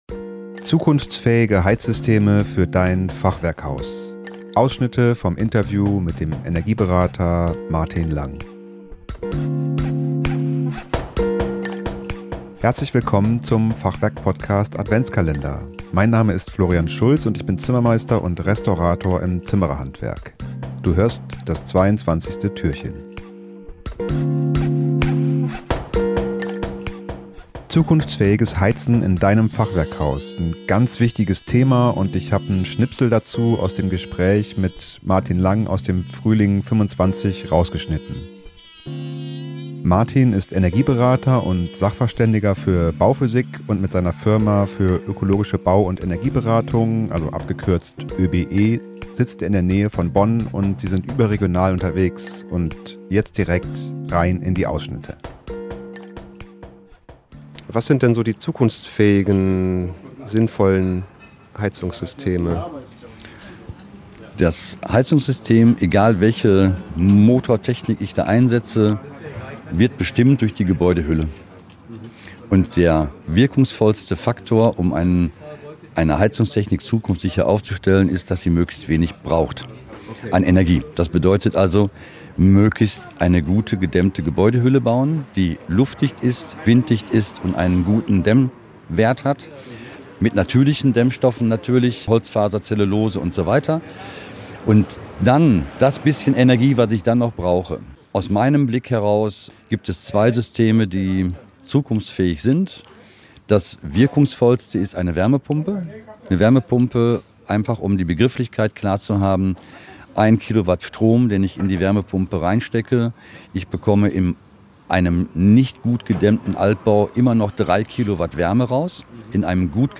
Zukunftsfähige Heizsysteme für Dein Fachwerkhaus - Interviewausschnitte im 22ten Türchen